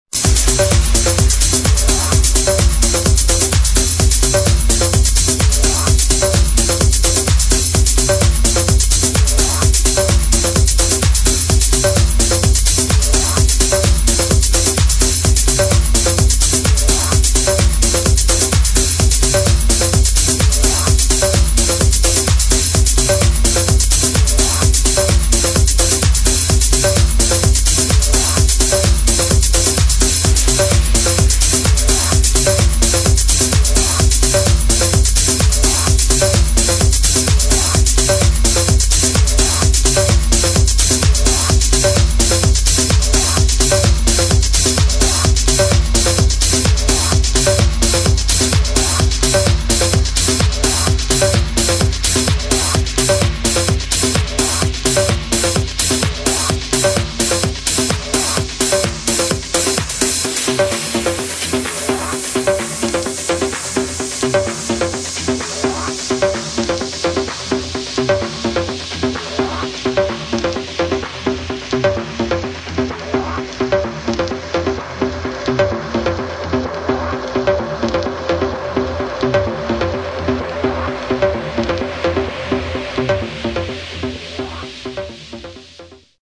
[ TECHNO / HOUSE ]